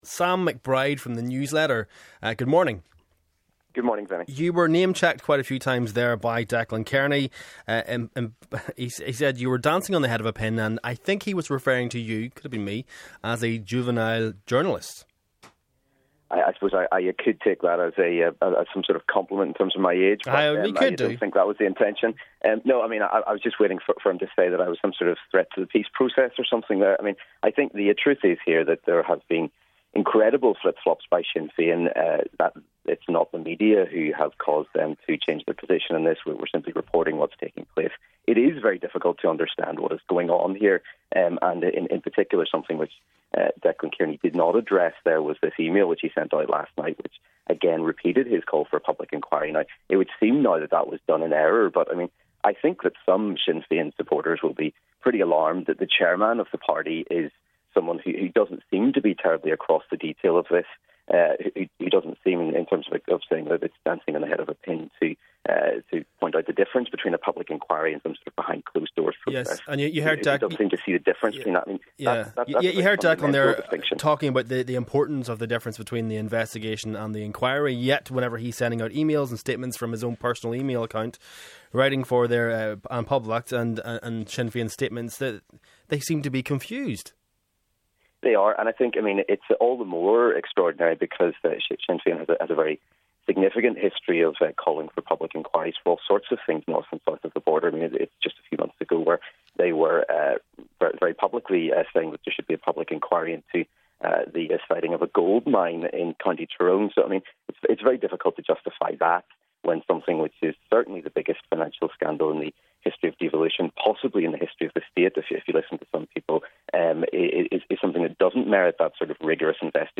We get reaction from commentators and Nolan callers.